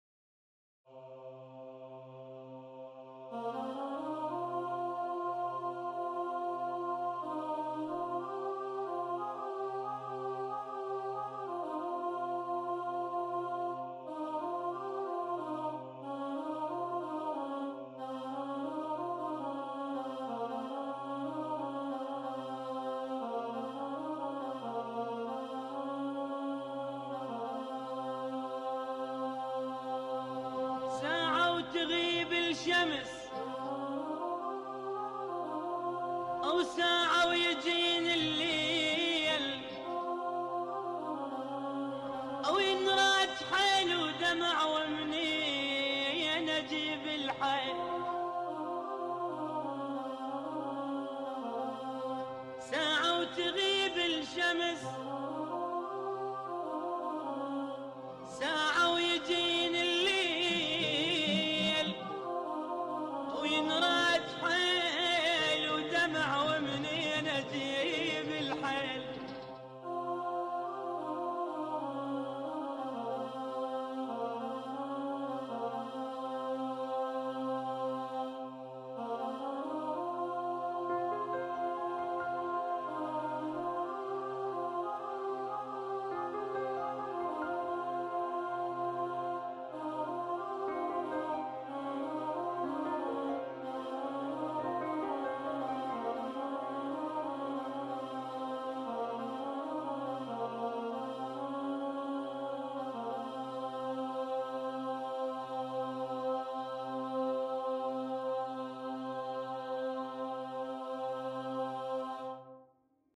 All parts practice file